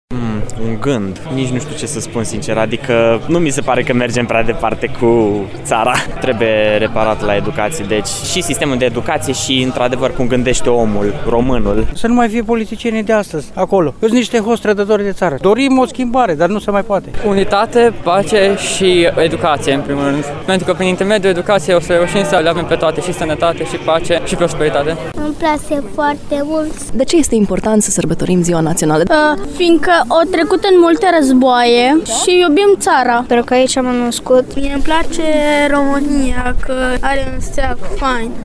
03.-Voxuri-parada.mp3